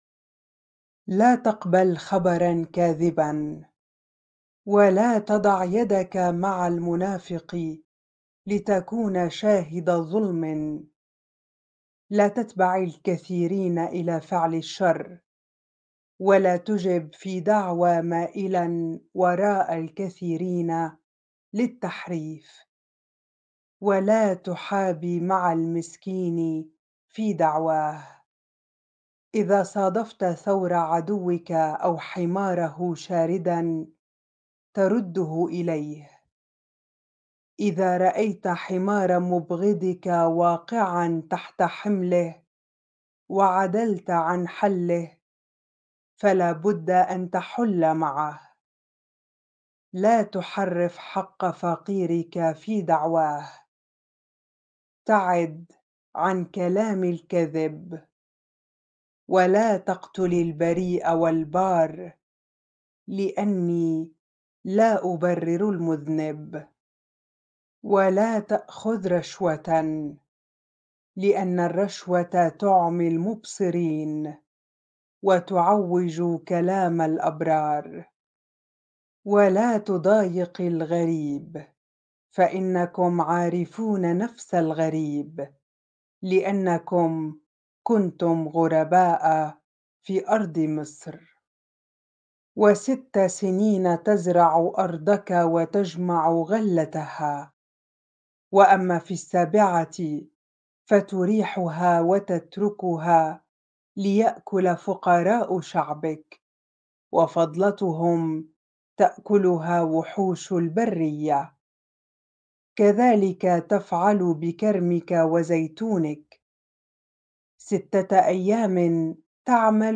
bible-reading-exodus-23 ar